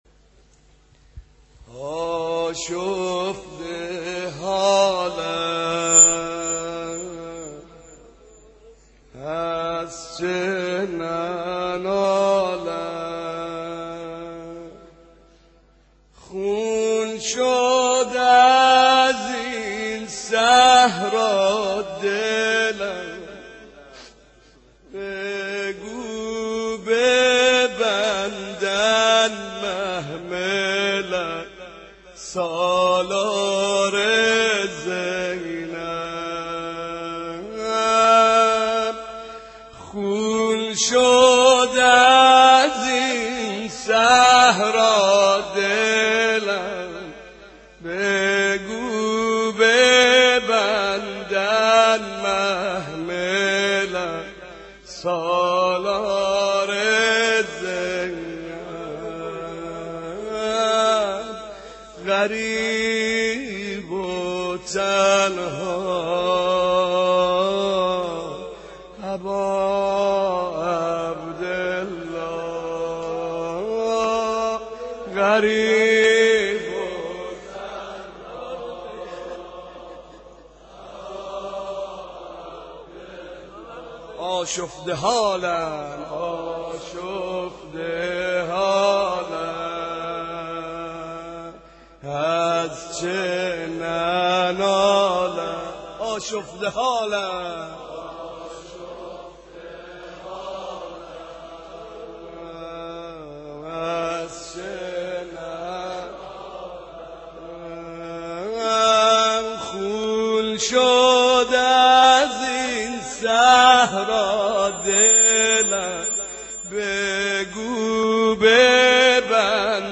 محرم شب دوم - ورود به کربلا مداح اهل بیت استاد